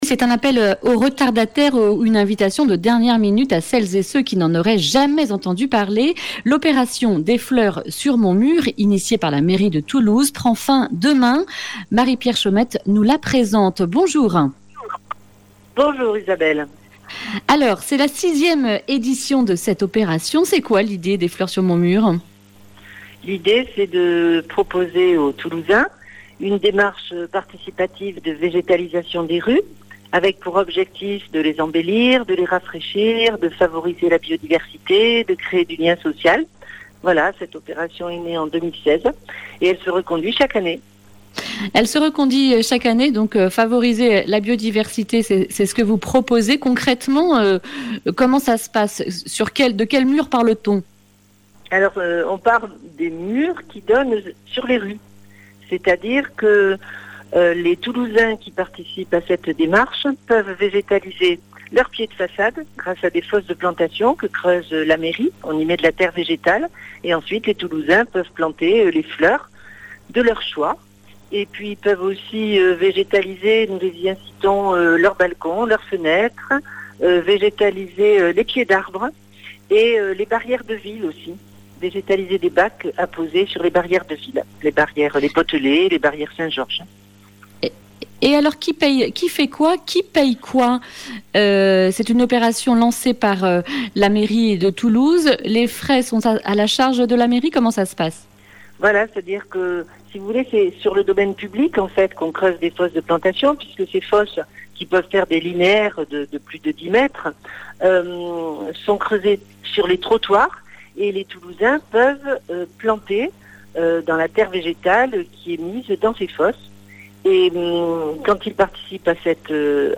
jeudi 4 juin 2020 Le grand entretien Durée 10 min